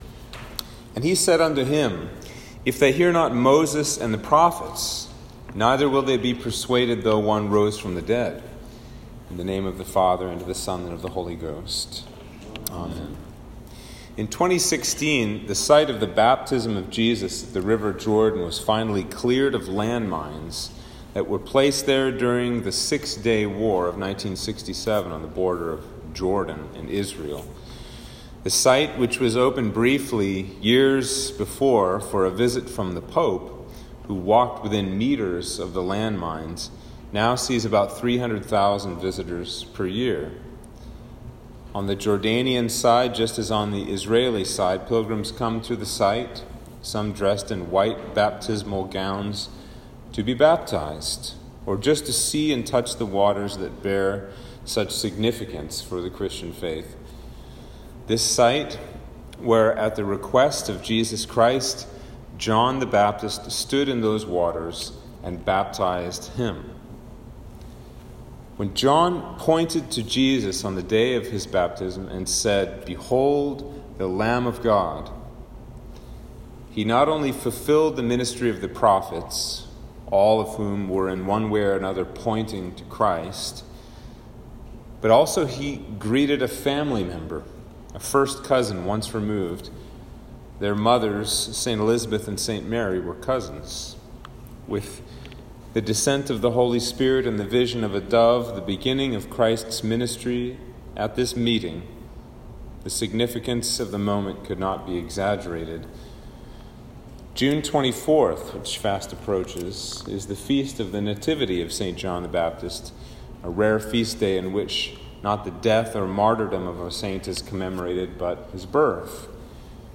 Sermon-for-Trinity-1-2021.m4a